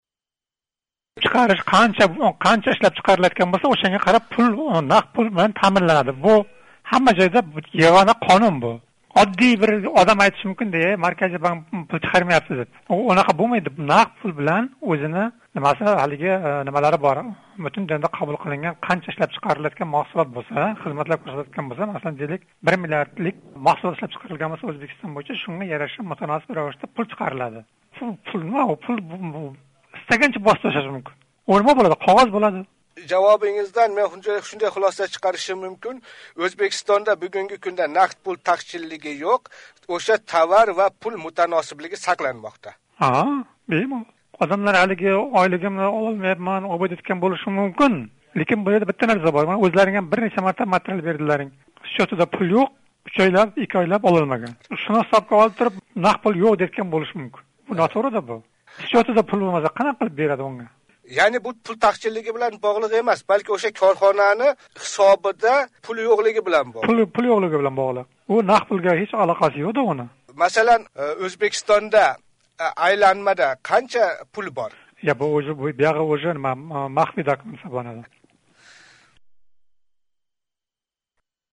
Марказий банк расмийси билан суҳбат